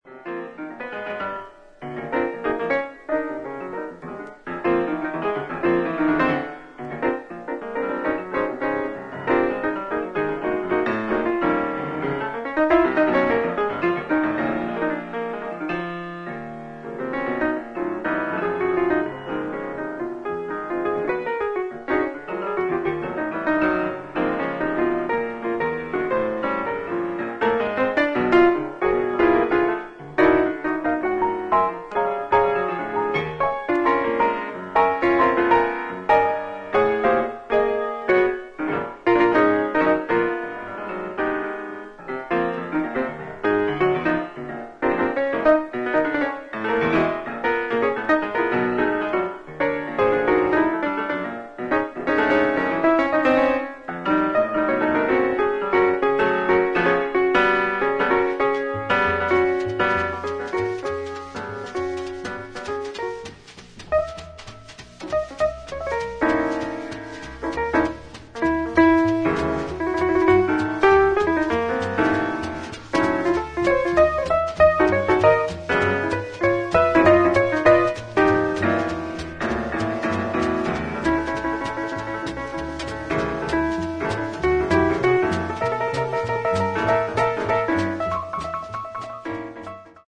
ジャズピアニスト
トランペット
テナー・サックス
フリージャズ黎明期における、エネルギッシュな演奏が堪能できます。